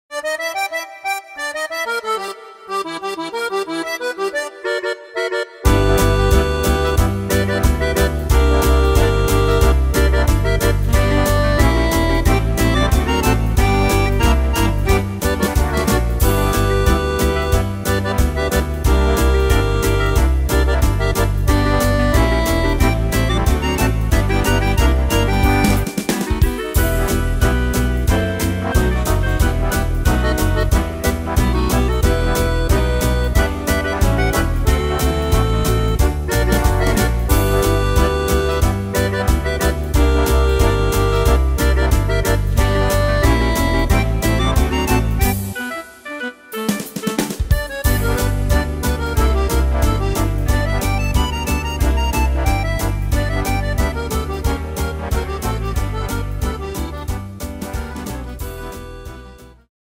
Tempo: 182 / Tonart: F-Dur